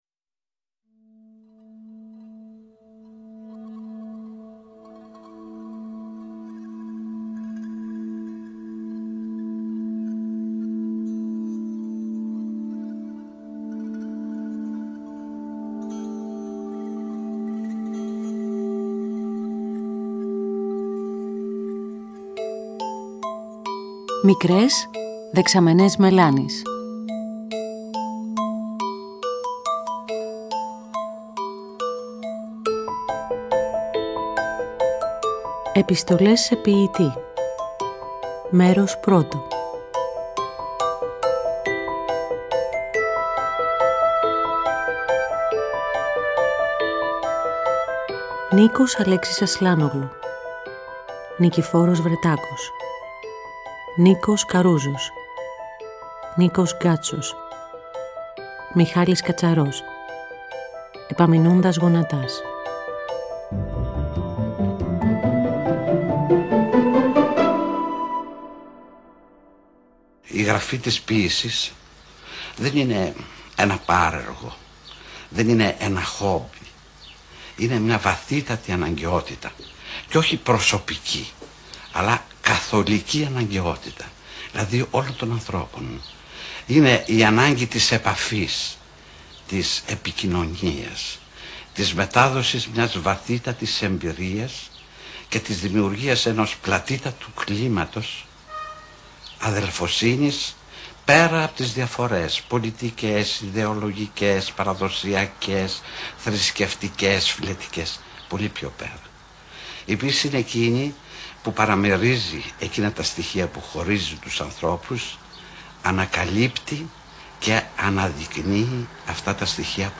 Ο τρυφερός λόγος του είναι πεζός και η διάθεσή ποιητική καθώς περνάνε από μπροστά μας στιγμές της καθημερινότητάς τους, εικόνες της γενέθλιας γης τους και η αθανασία των στίχων τους.
Ραδιοφωνικά, όλα τα παραπάνω συμβαίνουν υπό ήχους της σύγχρονης ελληνικής τζαζ μουσικής